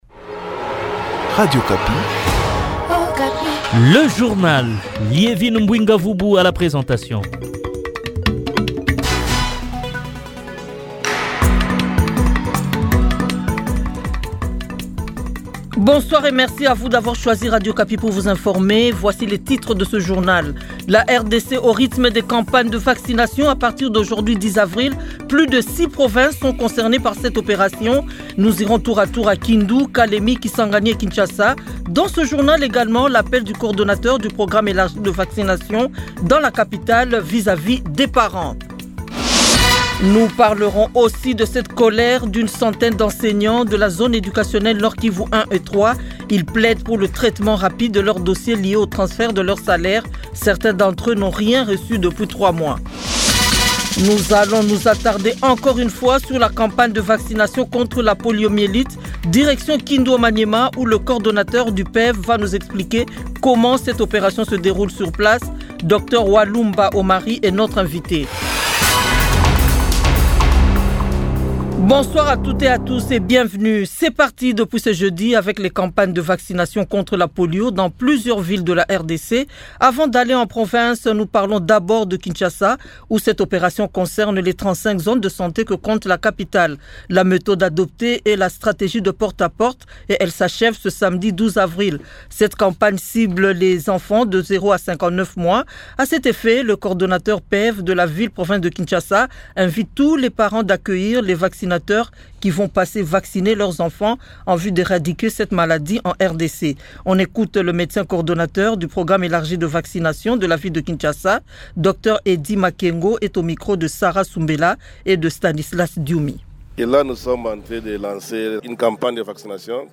Journal 18h jeudi 10 avril 2025